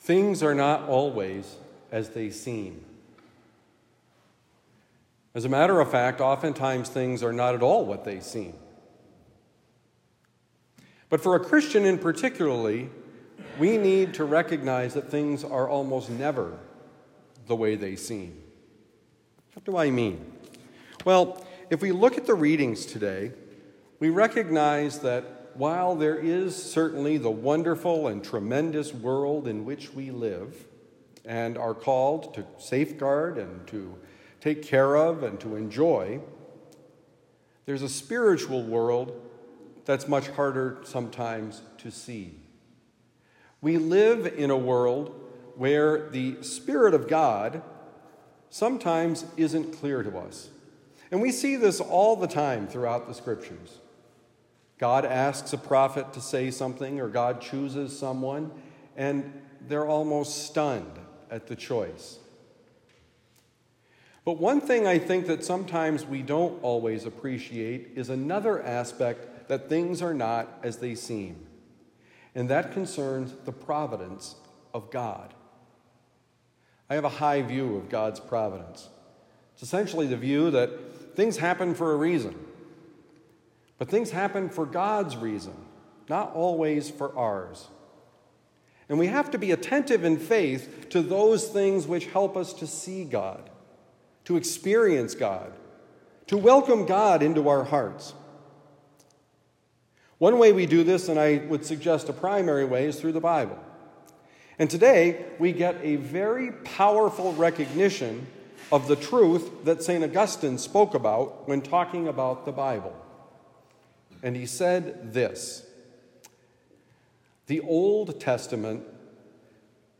Things are not always as they seem: Homily for Thursday, August 15, 2024